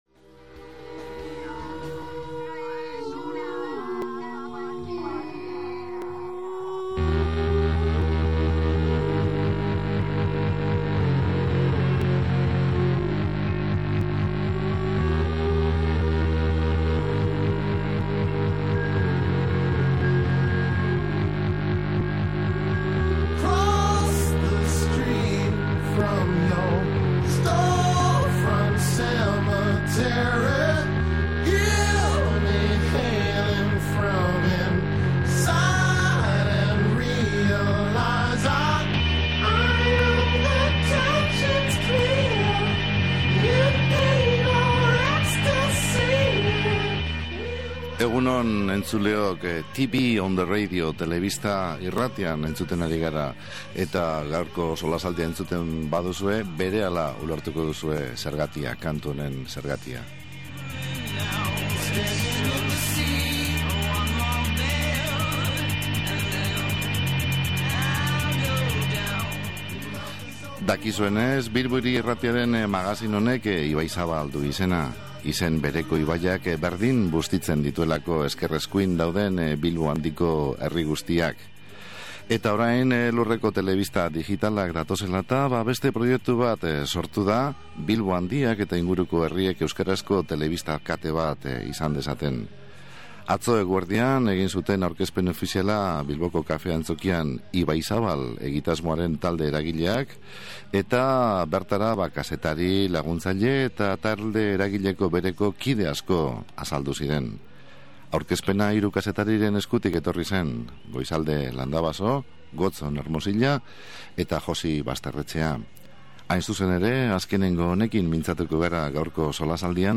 SOLASALDIA: “Ibaizabal”, Bilbo Handirako telebista egitasmoa